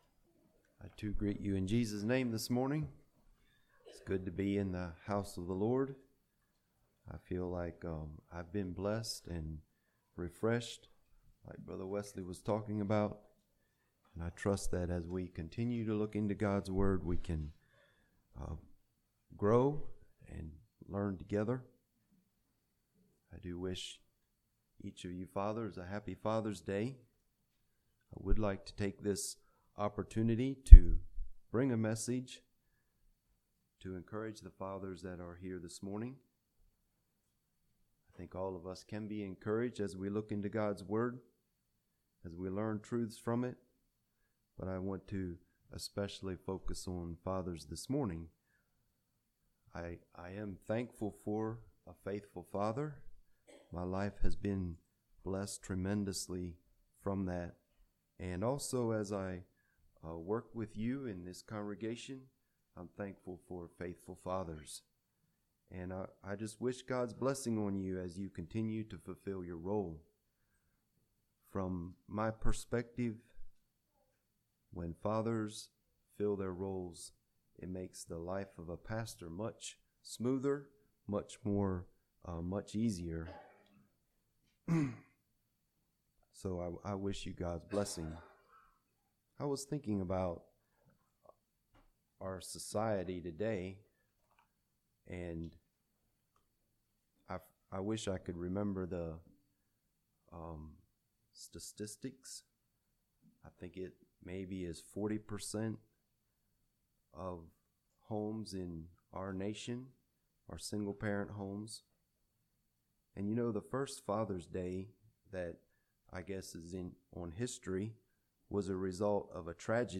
Play Now Download to Device Encouragement For Fathers Congregation: Dublin Speaker